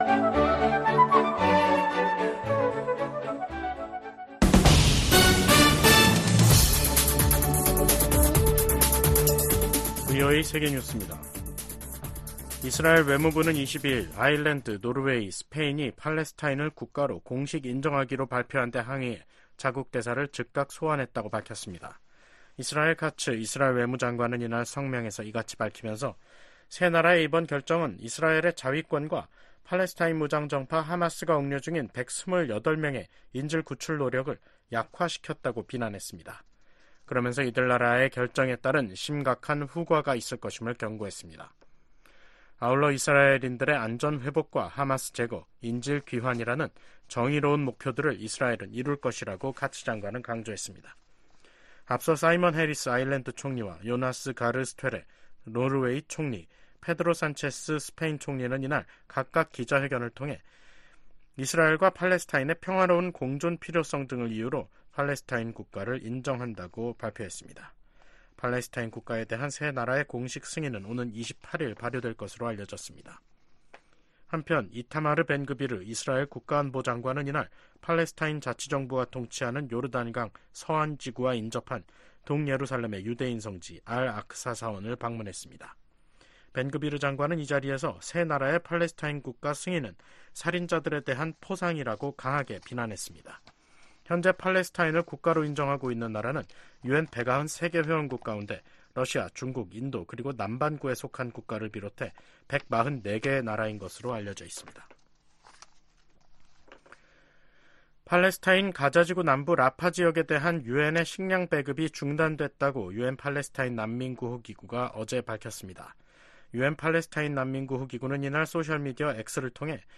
VOA 한국어 간판 뉴스 프로그램 '뉴스 투데이', 2024년 5월 22일 3부 방송입니다. 토니 블링컨 미국 국무장관이 러시아에 대한 북한의 직접 무기 지원을 비판했습니다. 미국 국방부는 미국과 동맹들이 한반도 긴장을 고조시킨다는 러시아의 주장을 일축하고, 미한일 협력이 역내 평화와 안보, 안정을 가져왔다고 강조했습니다. 미국과 영국, 호주에 이어 캐나다 정부도 북한과 러시아의 무기 거래를 겨냥한 제재를 단행했습니다.